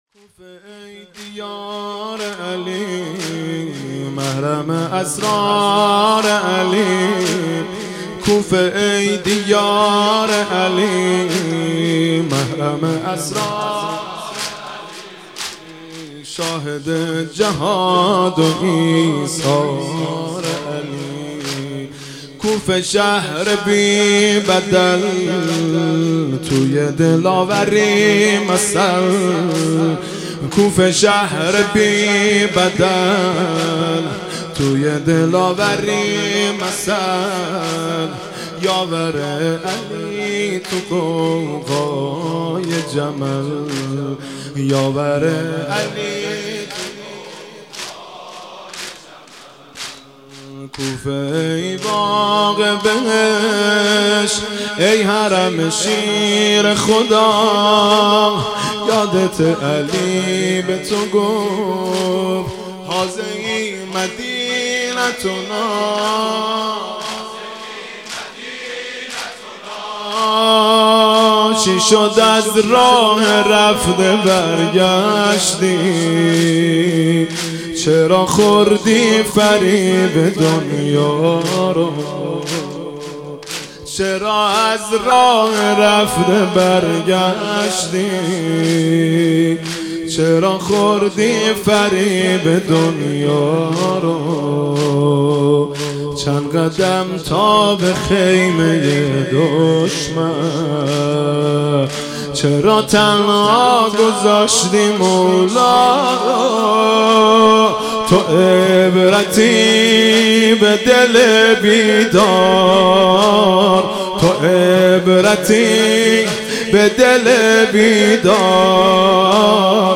مهدی رسولی، زمینه، کوفه ای دیار علی